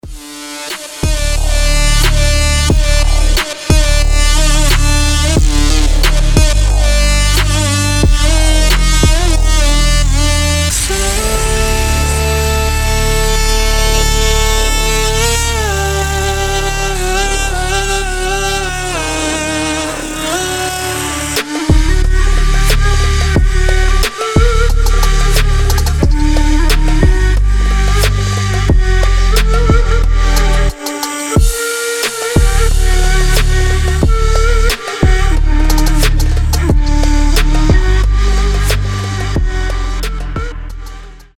• Качество: 320, Stereo
атмосферные
восточные
этнические
арабские
дудук
Атмосферный arabic trap/ indian trap